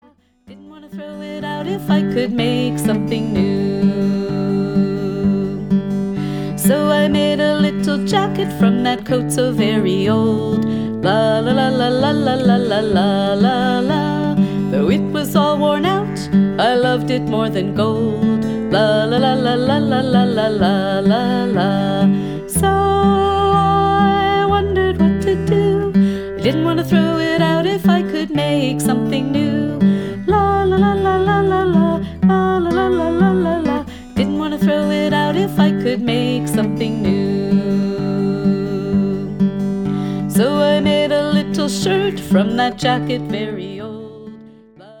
Yiddish Folk Song